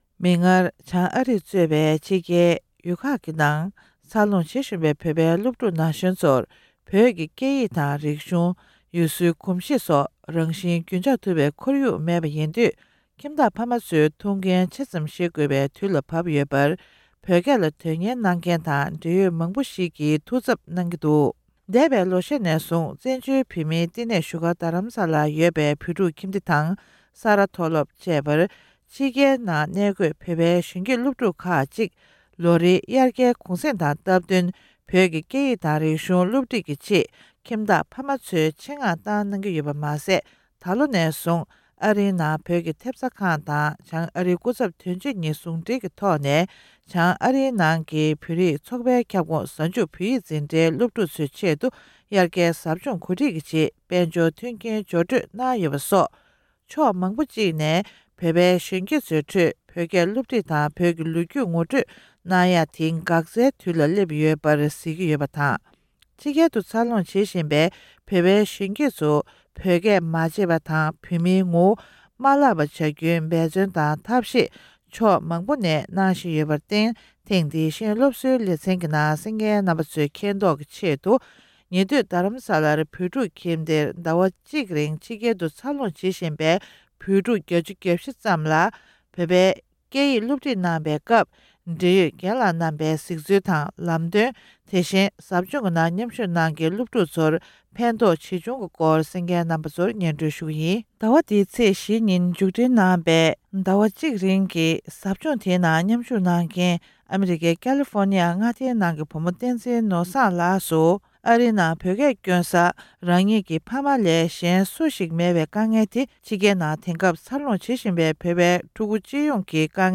འབྲེལ་ཡོད་མི་སྣ་ཁག་ཅིག་གི་ལྷན་གླེང་མོལ་གནང་བར་གསན་རོགས